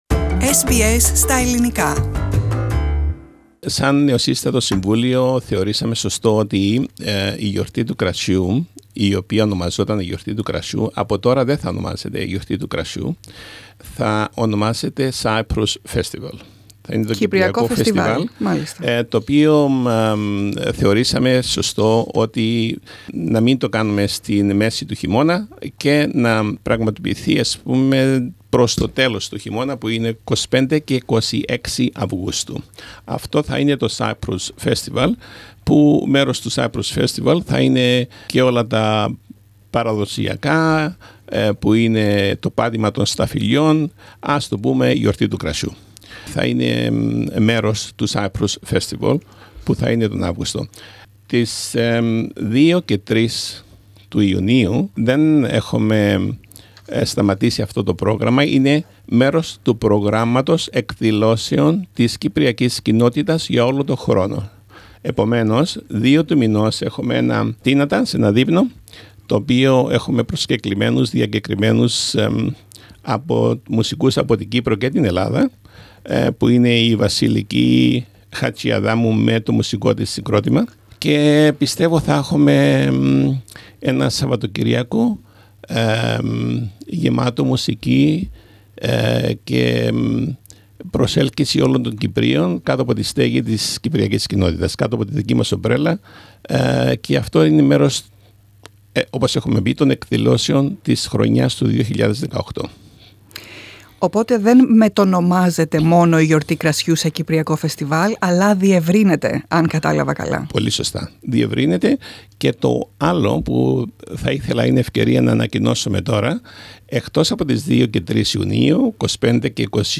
Στο εξής η Γιορτή Κρασιού θα μετονομαστεί σε Κυπριακό Φεστιβάλ ενώ φέτος οι εκδηλώσεις του φεστιβάλ άρχισαν το Σαββατοκύριακο 2 & 3 Ιουνίου και θα συνεχιστούν έως τον Οκτώβριο. Περισσότερα ακούμε στην συνομιλία